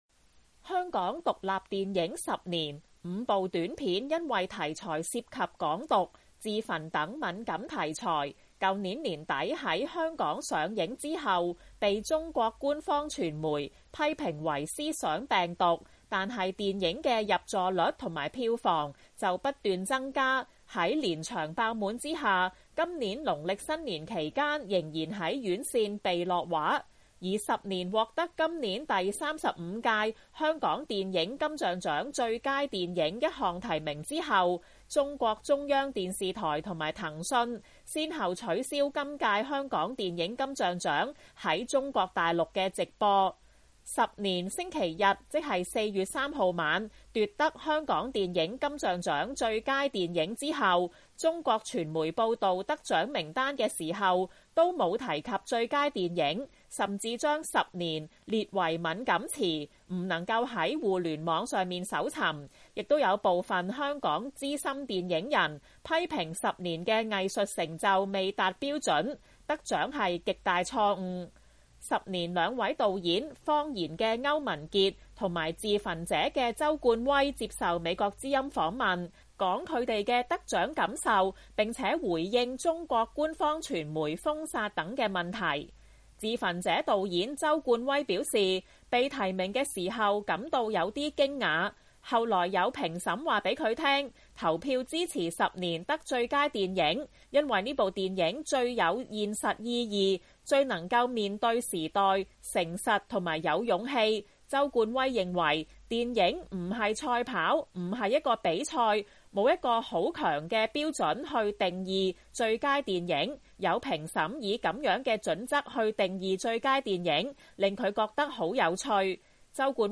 以5部有關香港未來10年(2025年)政治、生活環境想像的短片，組合而成的獨立電影《十年》，最近獲香港電影金像奬最佳電影奬，引起各界關注，並受到中國官方傳媒封殺。兩位《十年》導演接受美國之音訪問表示，得奬是多一個機會去表達香港人的無懼，也希望香港電影界堅持無懼及誠實的創作，不要因為迎合中國的電影審查而放棄世界市場。